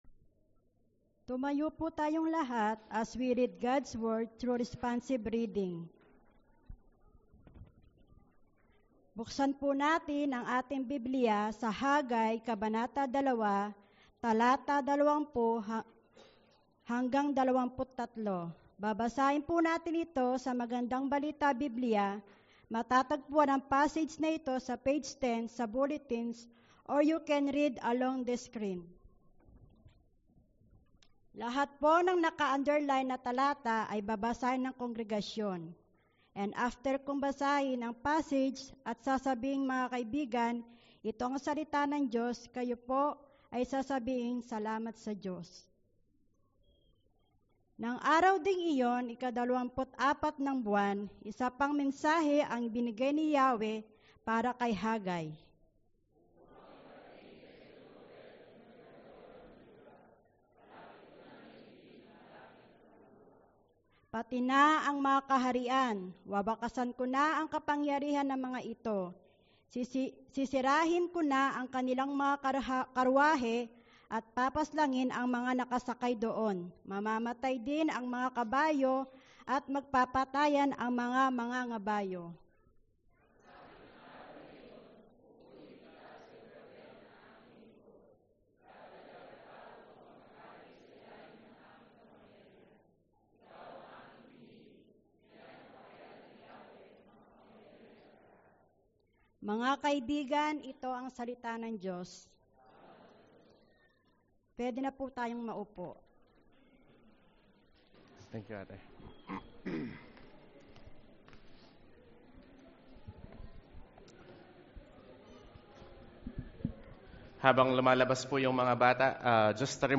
April-28-Sermon.mp3